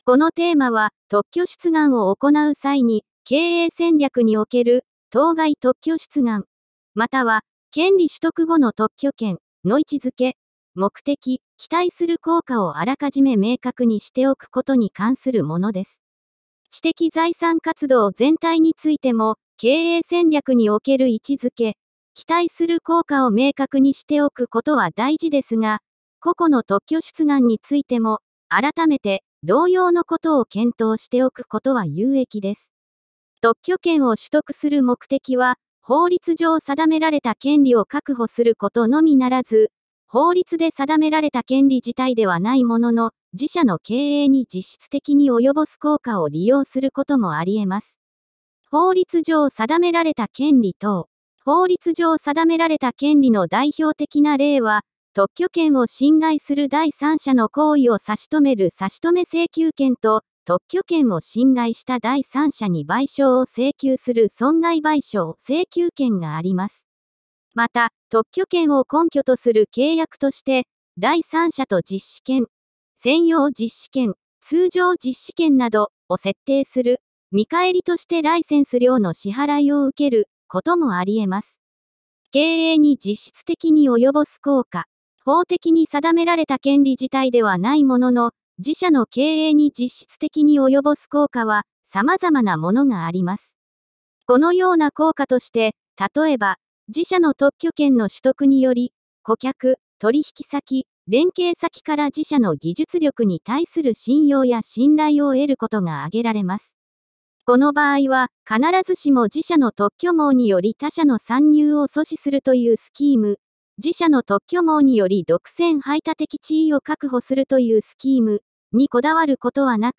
テーマの説明音声データ＞＞